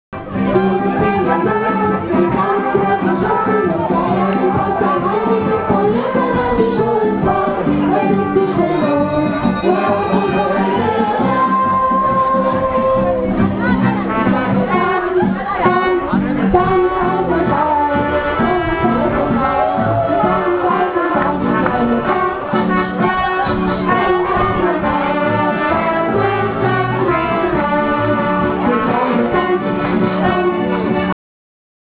dort wird zünftig bayrisch gefeiert
Mit Musik: Bitte auf ">" oder